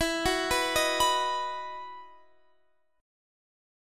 EM7sus2 Chord (page 2)
Listen to EM7sus2 strummed